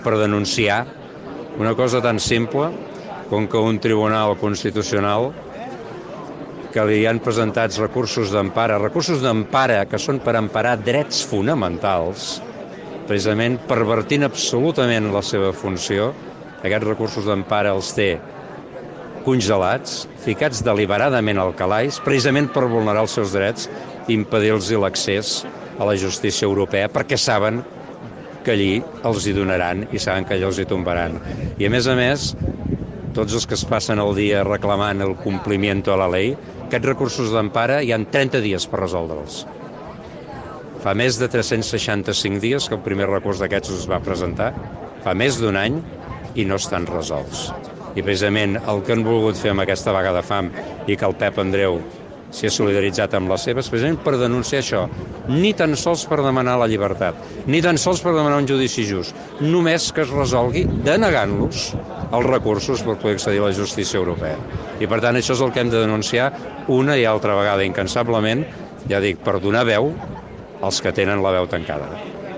La concentració de suport s’ha celebrat aquest divendres a les 8 del vespre amb diversos parlaments, entre aquests el del batlle montblanquí que va assegurar trobar-se bé i es va mostrar ferm amb la decisió presa.
Un d’ells va ser el montblanquí Eusebi Campdepadrós, secretari primer de la mesa del Parlament de Catalunya, que va assegurar que el Tribunal Constitucional està vulnerant els drets dels presos congelant els seus recursos d’empara per impedir que accedeixin a la justícia europea.
ÀUDIO: Eusebi Campdepadrós denuncia la vulneració de drets dels presos